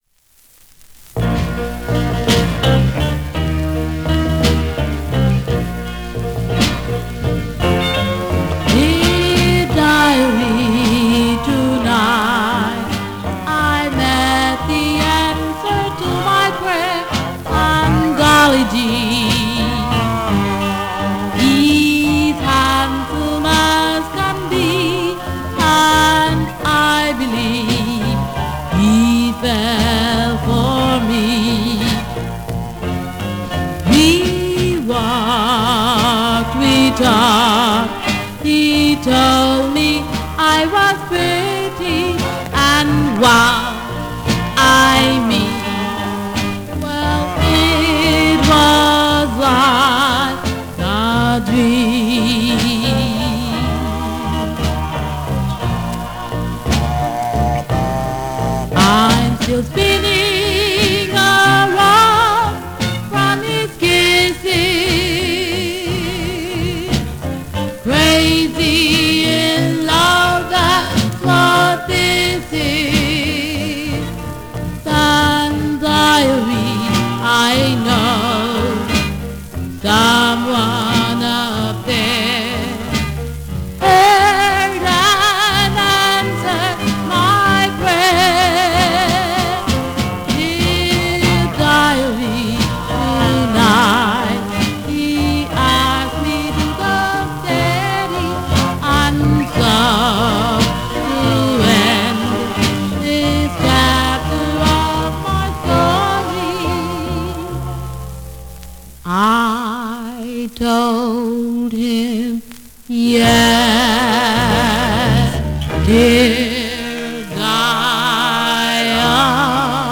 a black group from East Austin